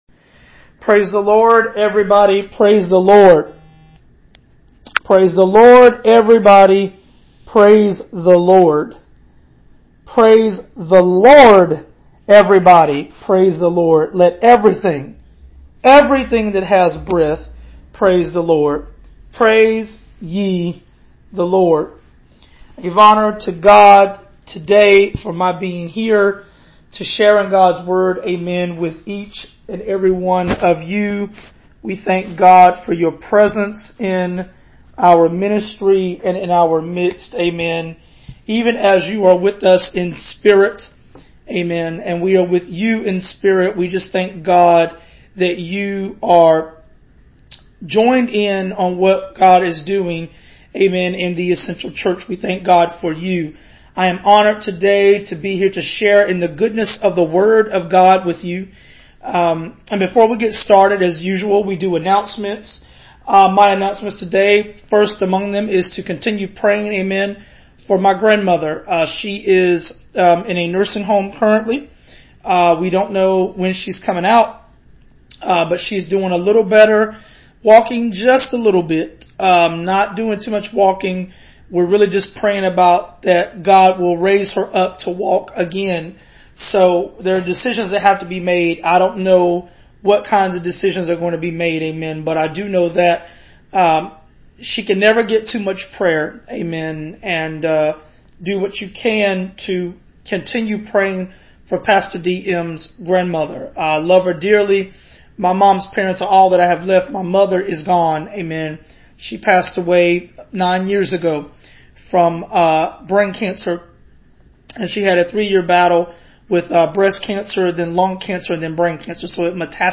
You can find the rest of the series, Parts 1A through 1F, on our Audio Sermons and Bible Studies page and below: Part 1A Part 1B Part 1C Part 1D Part 1E Part 1F I pray this doctrine teaching finds you well.